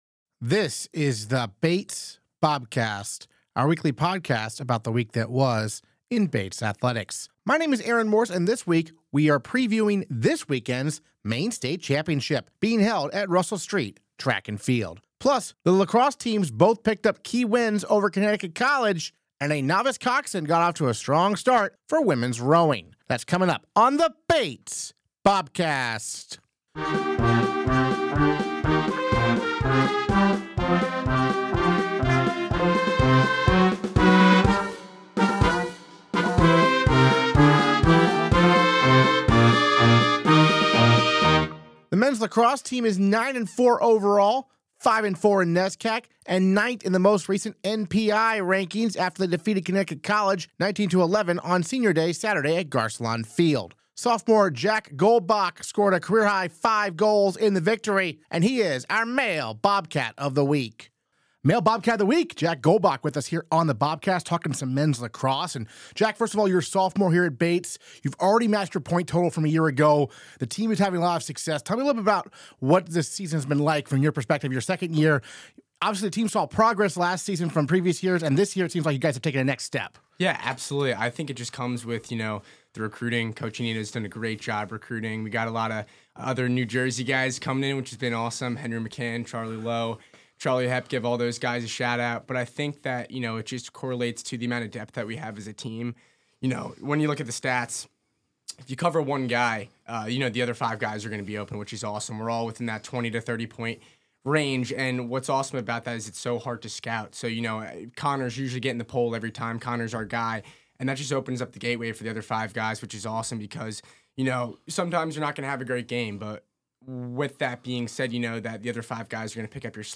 Interviews this episode